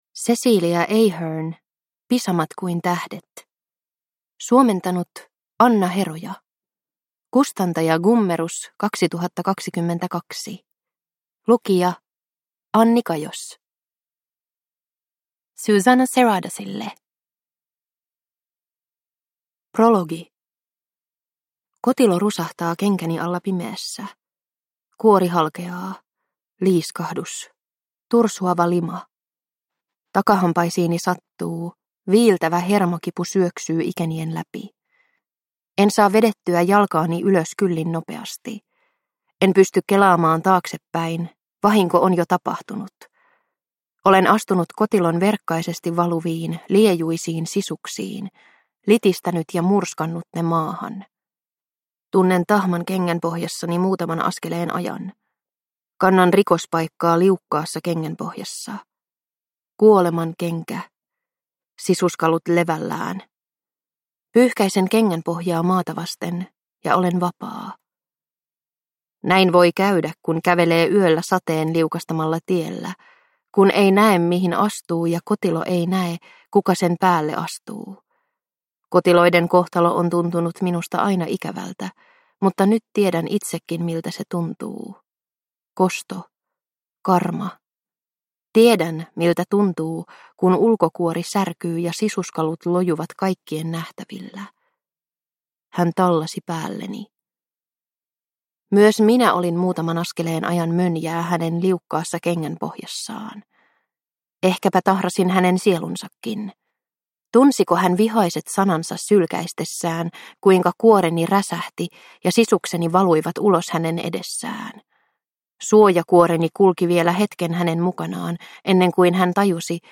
Pisamat kuin tähdet – Ljudbok – Laddas ner